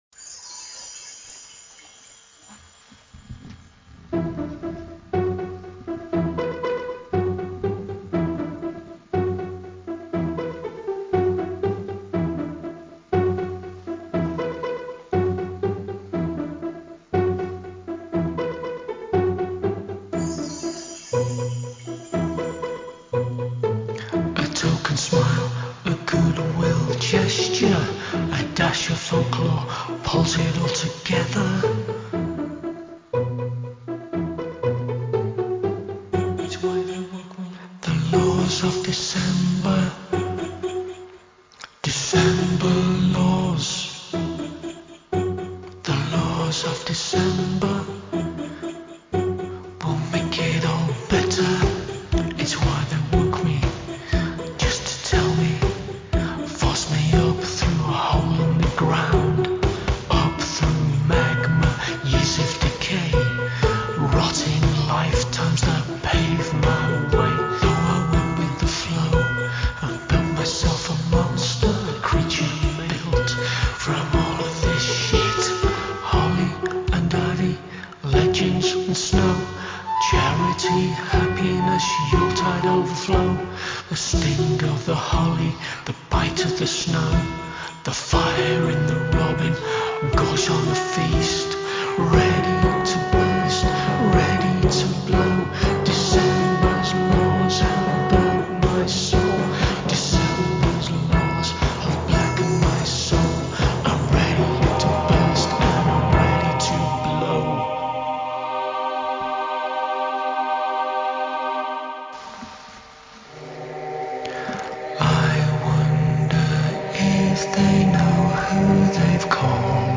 It's a fantasy narrative tale in musical form, with the narrator being a dark winter spirit summoned from the depths, who acts as a krampus/malicious Pied Piper type character. The mood is eerie, maybe at a PG film level or evil childcatcher in Chitty Chitty Bang Bang level.
Musically it's a 10 minute track, focuses on distinct sections featuring pizzicato strings, harpsichord, minimal electronic sounds, with an overlay of the half sung & half spoken vocals.